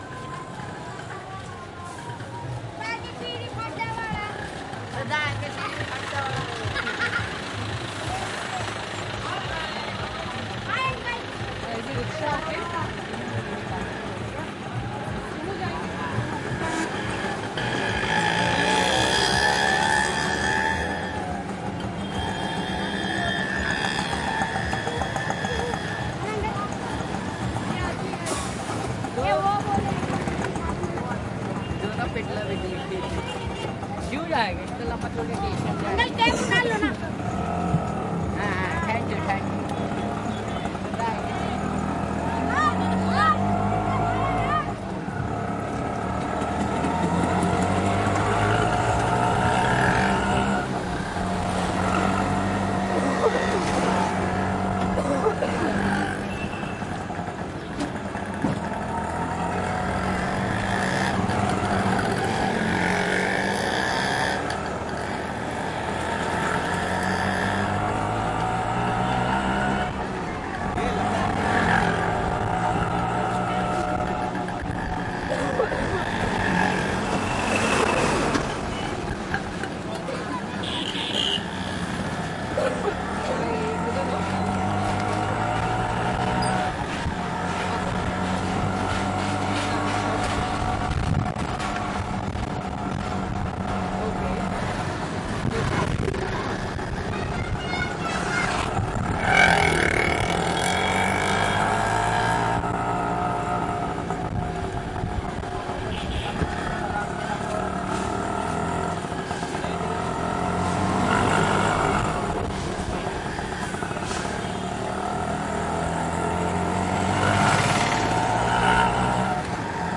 车内氛围
描述：汽车城市交通噪声街道的现场录音氛围
Tag: 环境 交通 街道 汽车 领域 - 记录 噪音 城市